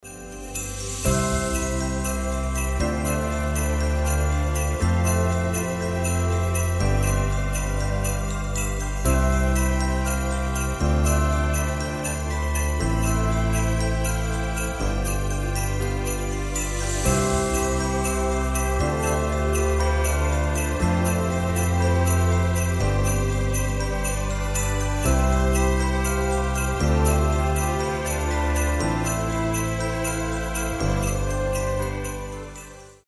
• Качество: 320, Stereo
спокойные
без слов
красивая мелодия
праздничные
колокольчики
рождественские